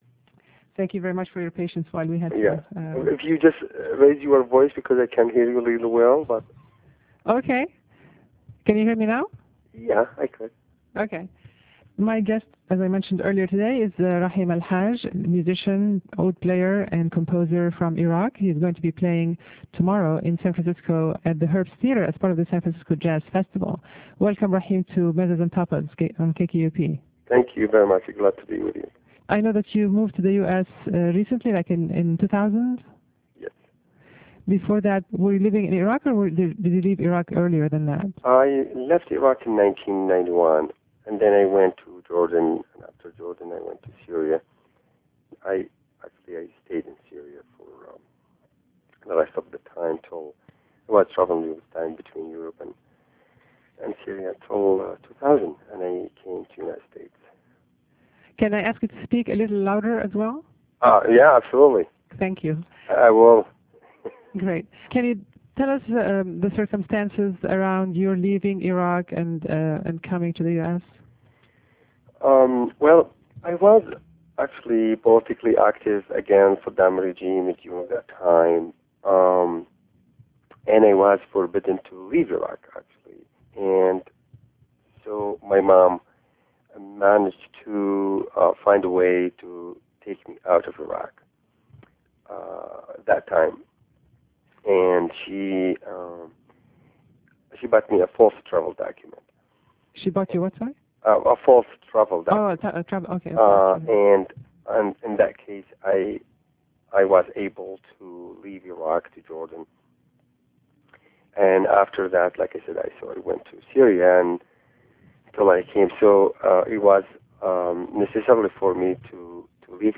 Interview with Rahim AlHaj - phone interview on the air Wed Nov 5th, 2008
RahimAlHajInterviewAll.ram